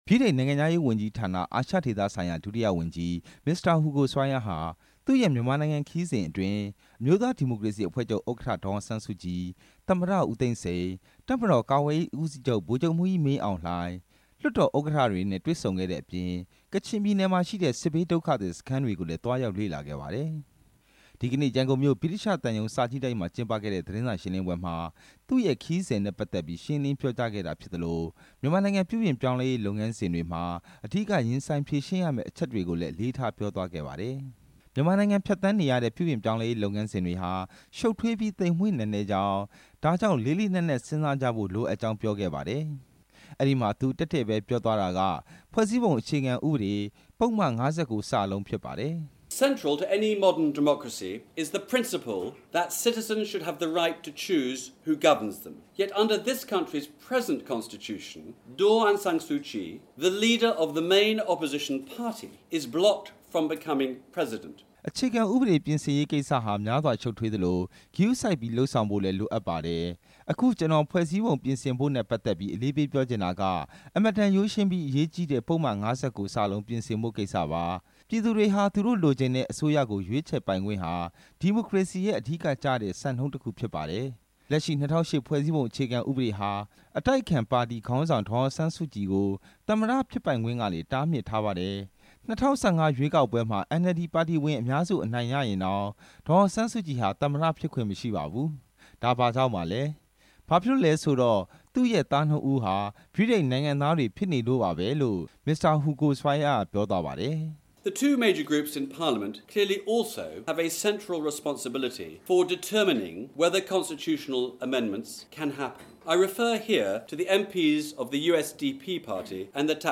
သတင်းစာရှင်းလင်းပွဲအကြောင်း တင်ပြချက်